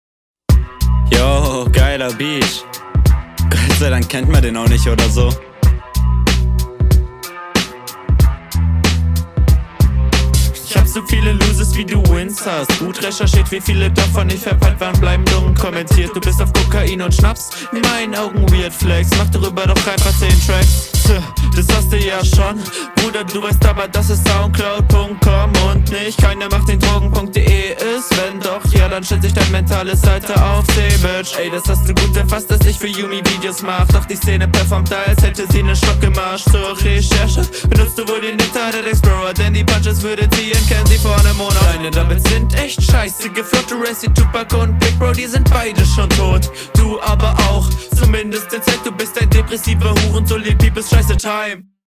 Die erste Hälfte und die 2. Teilweise sind eigentlich sehr gut geflowt, jedoch immer noch …